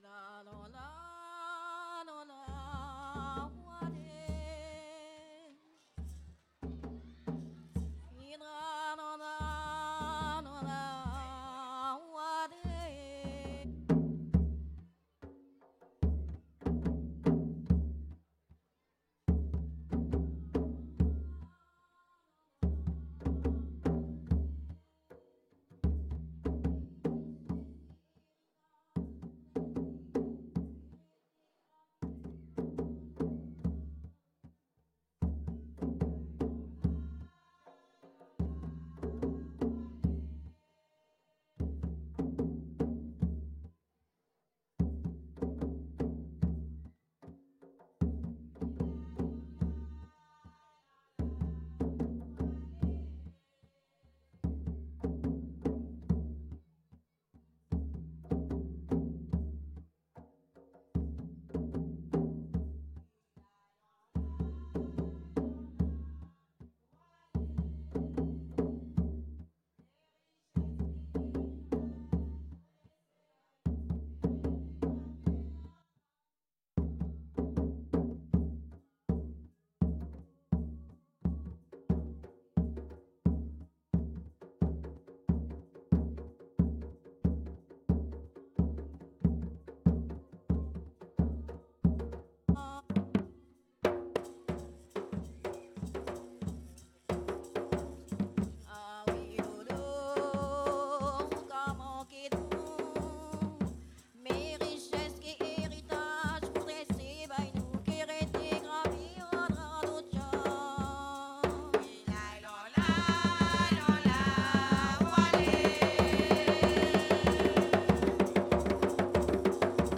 La voix saute et n'est pas toujours audible du à un problème lors de l'enregistrement.
danse : léròl (créole)
Pièce musicale inédite